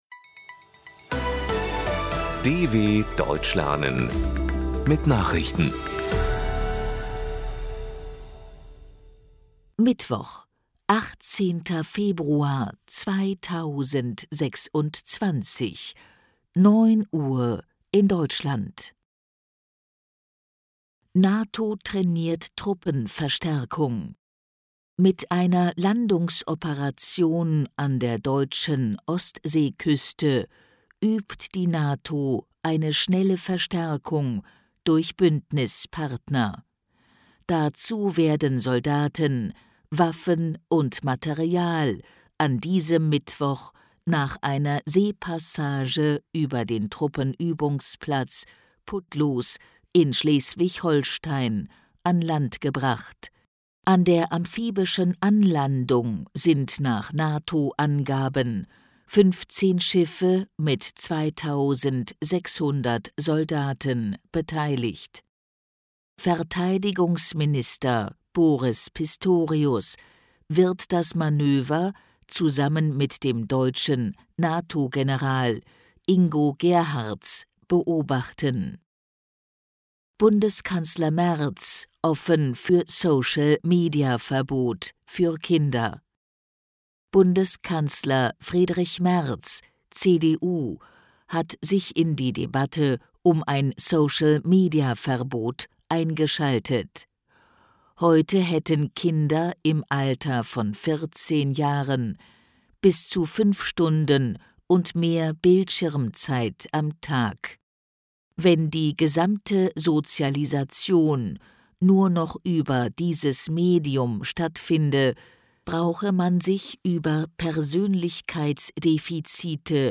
18.02.2026 – Langsam Gesprochene Nachrichten
Trainiere dein Hörverstehen mit den Nachrichten der DW von Mittwoch – als Text und als verständlich gesprochene Audio-Datei.